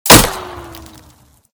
wood01gr.ogg